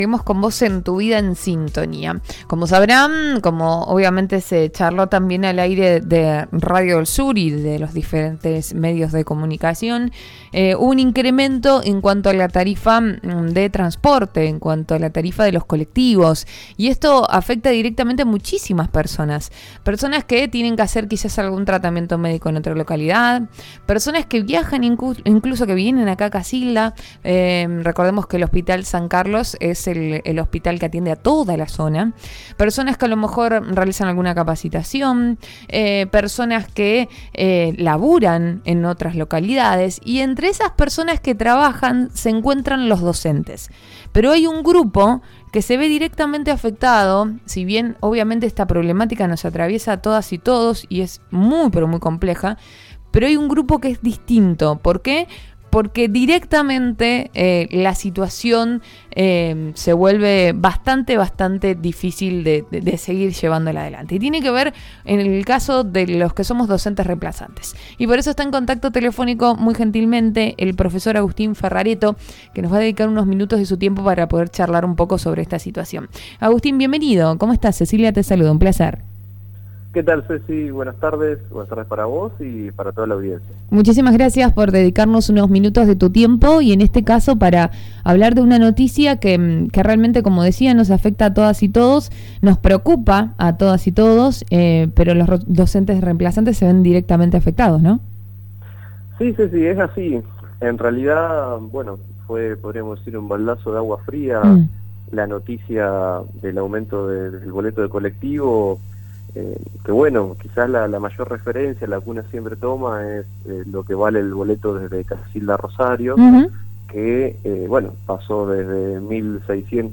Al respecto, Radio del Sur FM 90.5, a través del programa Tu Vida En Sintonia, dialogó con el profesor